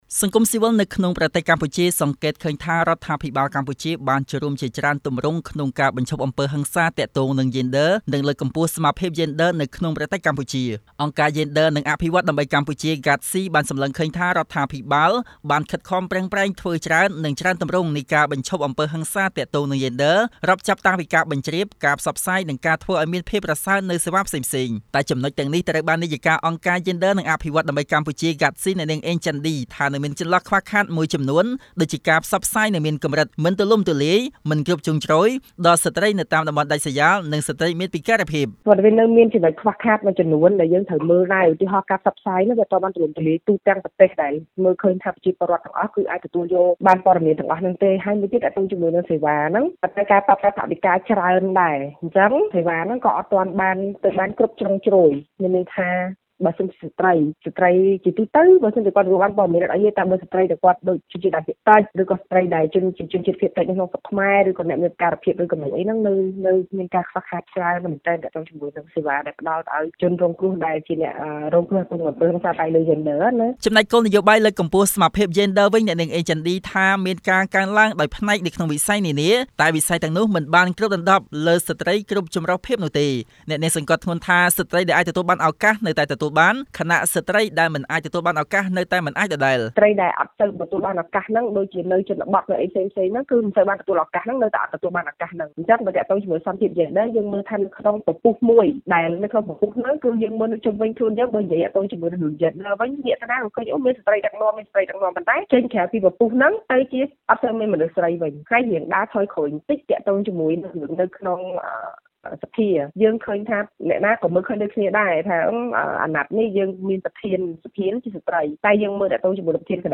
ជូននូវសេចក្ដីរាយការណ៍៖